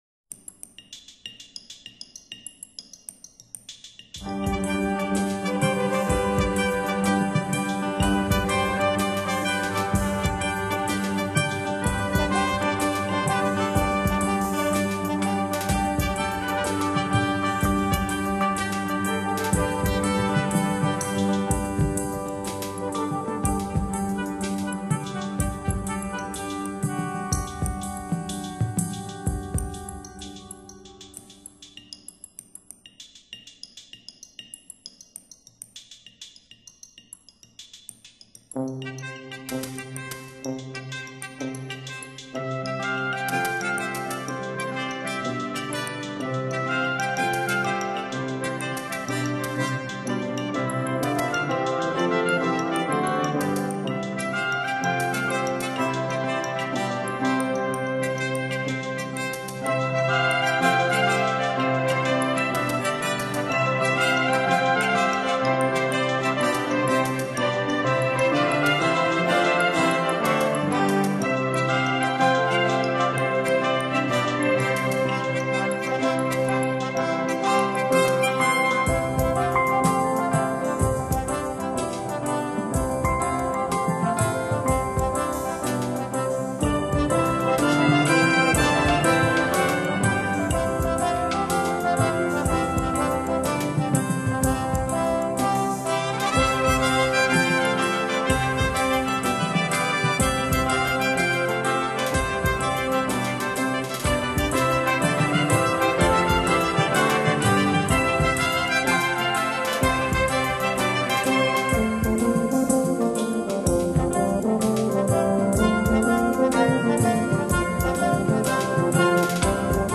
著名的试音碟，动用了类似虚拟环绕的技术。